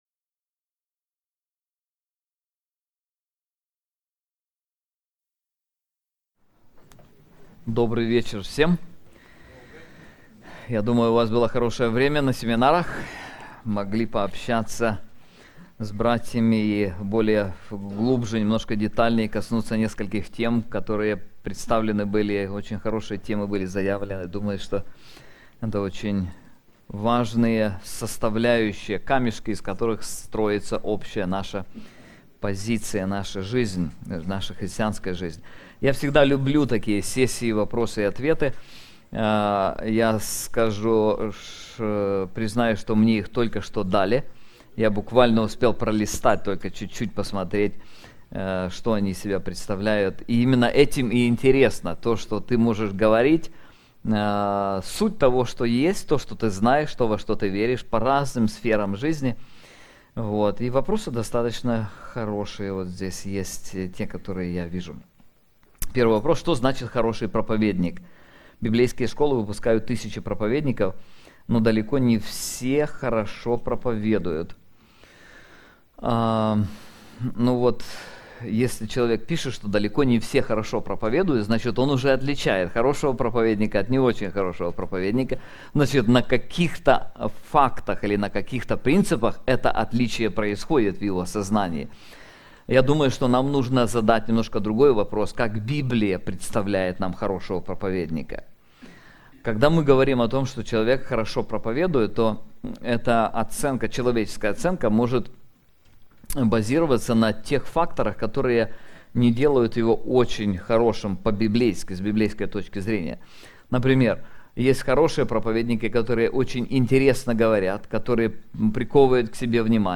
Ответы на вопросы